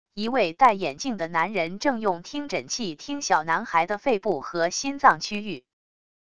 一位戴眼镜的男人正用听诊器听小男孩的肺部和心脏区域wav音频